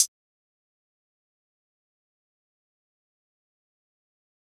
Hihat (Trillest).wav